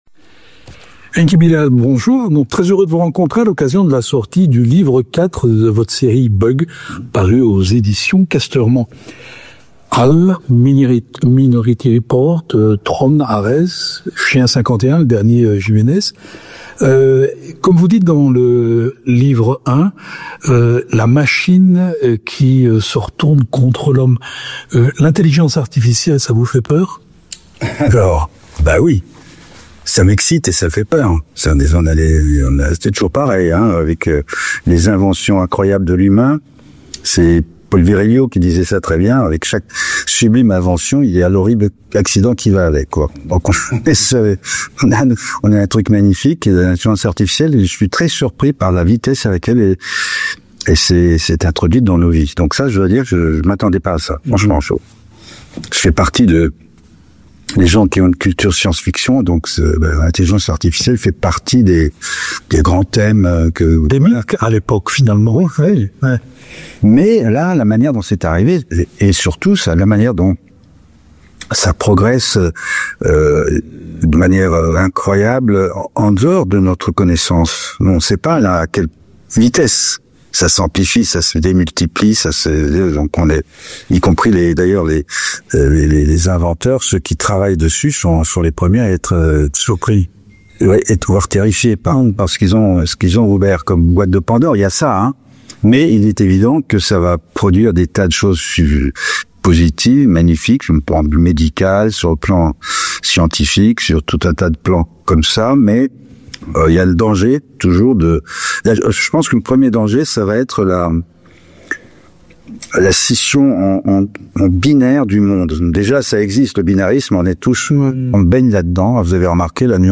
À l’occasion de la sortie du quatrième livre de « Bug», rencontre avec l’auteur qui y aborde dans cette BD des thèmes aussi profonds que les impacts de la technologie sur l’humanité, les enjeux écologiques et les dystopies futures.